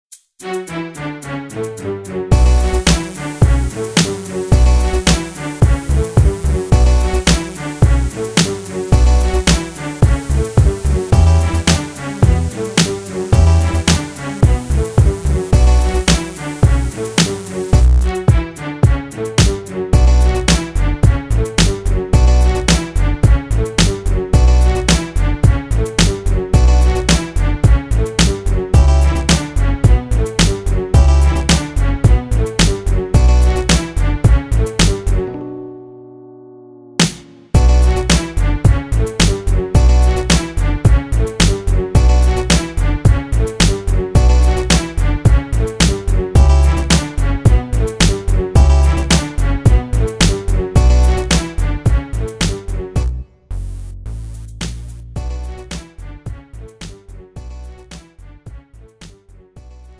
음정 (-1키)
장르 가요 구분 Lite MR